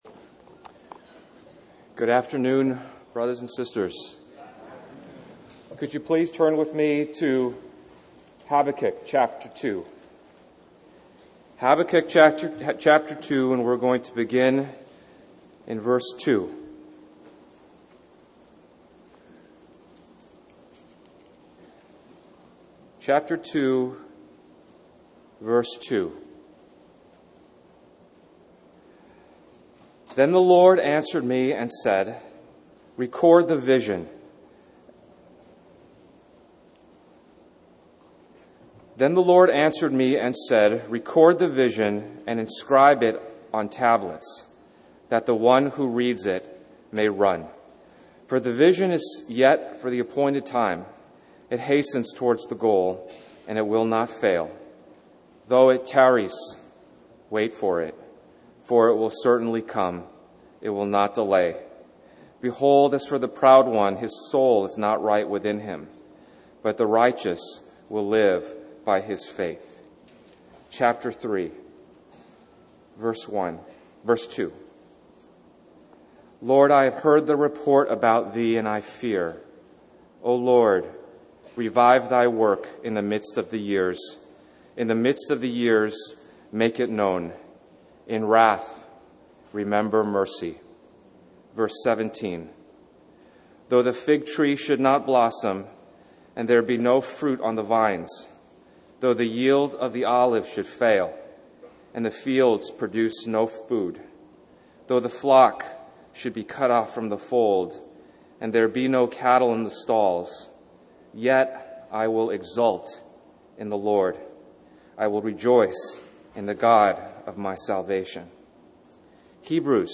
Harvey Cedars Conference We apologize for the poor quality audio